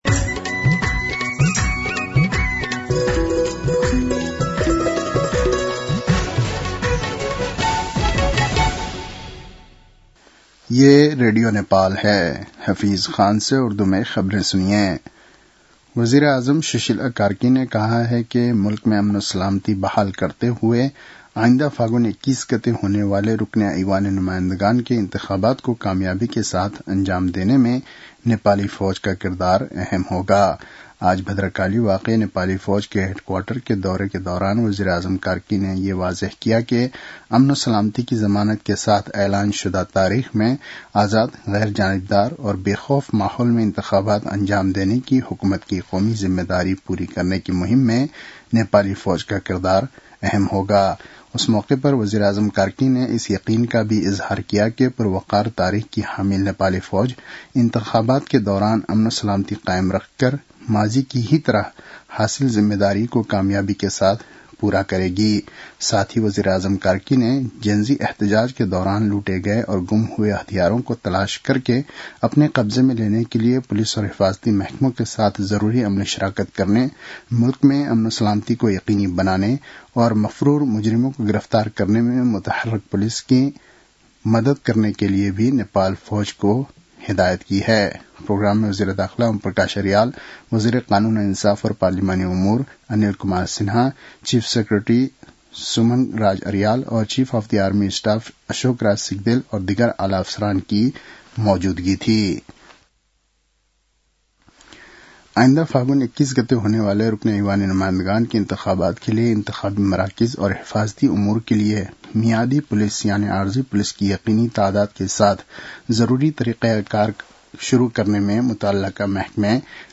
उर्दु भाषामा समाचार : १२ मंसिर , २०८२